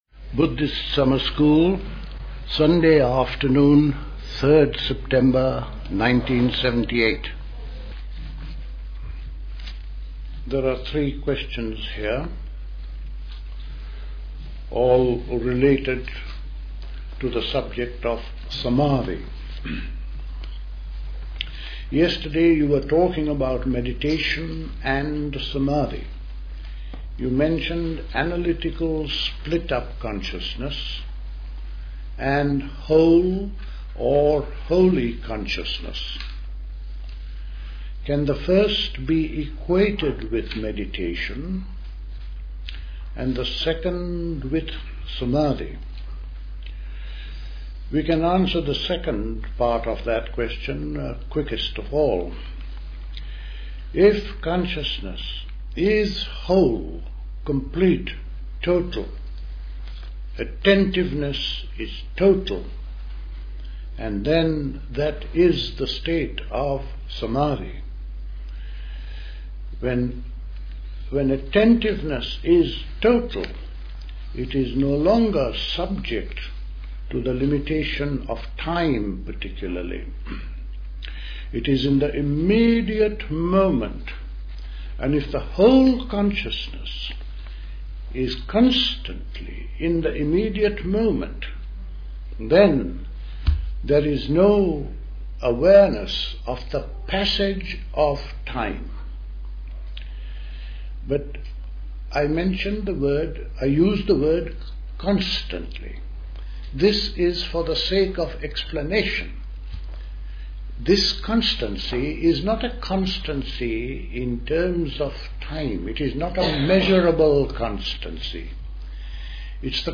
A talk
at High Leigh Conference Centre, Hoddesdon, Hertfordshire
The Buddhist Society Summer School Talks